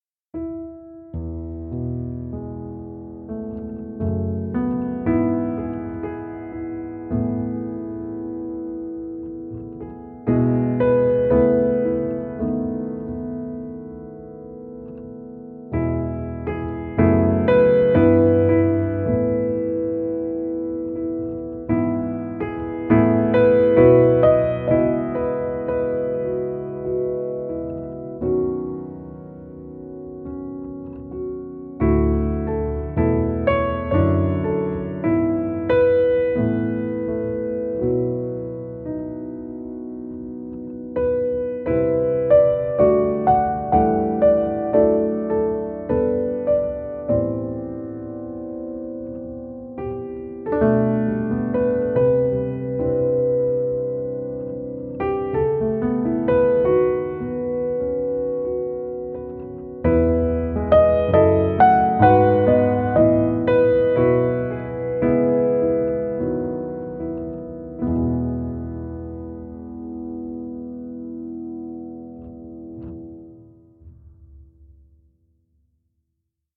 Deeply sampled grand piano with a rich, versatile tone.
Audio Demos